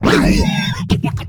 hurt1.ogg